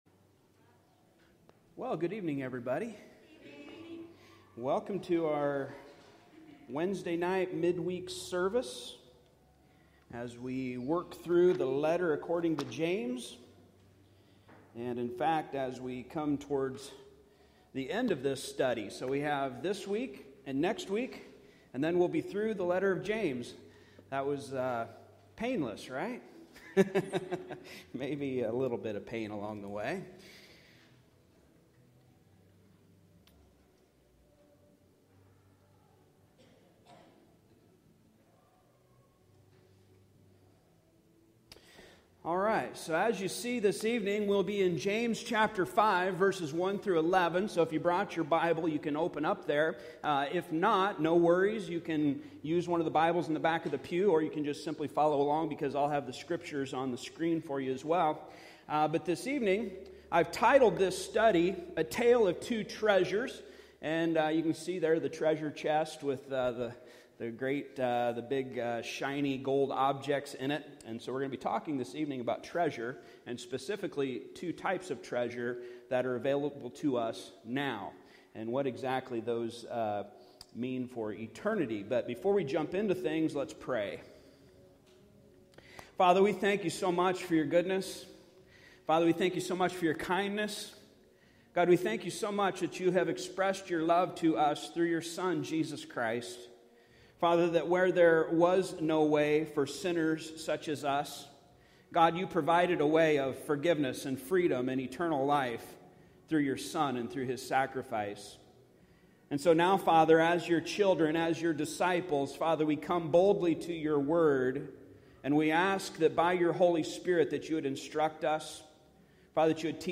Service Type: Midweek Service Topics: Patience , Tithe , Wealth